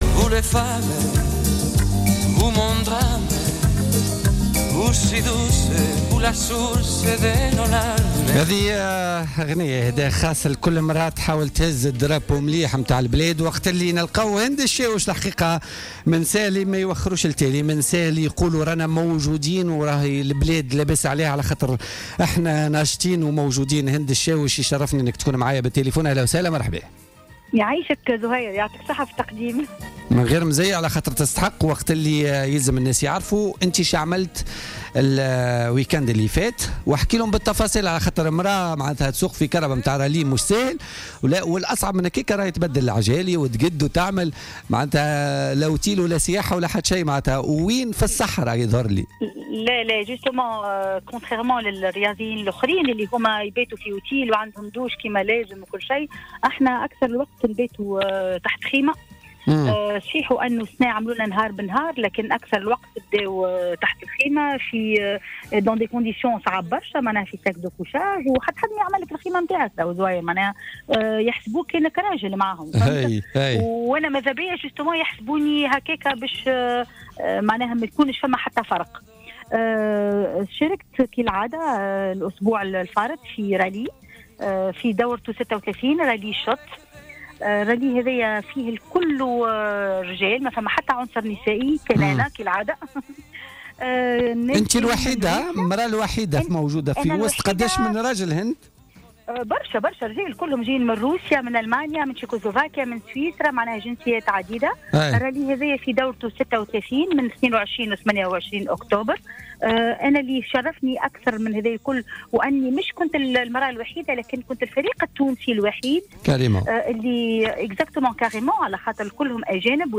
في تصريح لها خلال برنامج بوليتيكا